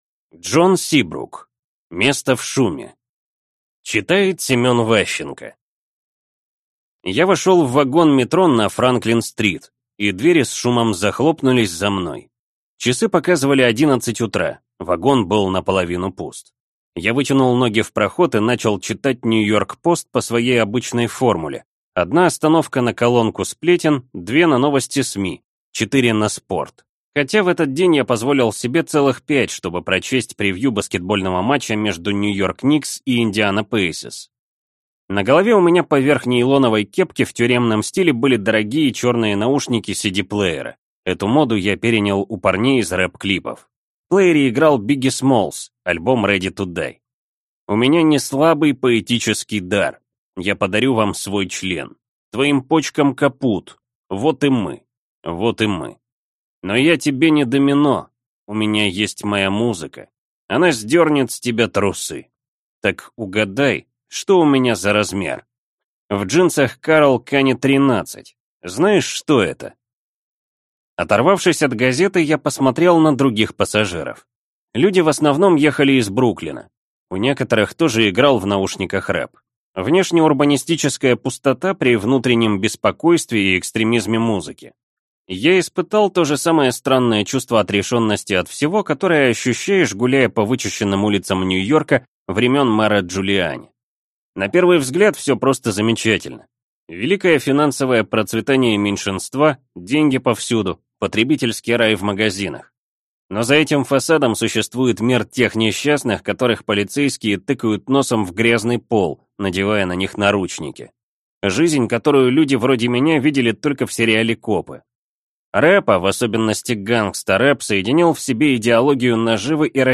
Аудиокнига Место в шуме | Библиотека аудиокниг